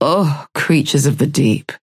Calico voice line - Ugh. Creatures of the Deep.